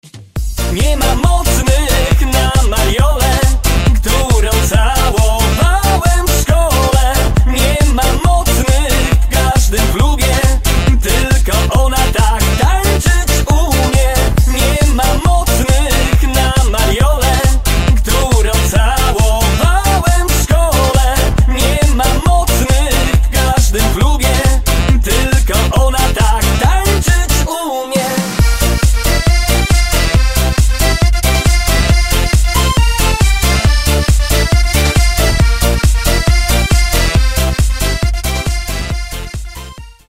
Disco polo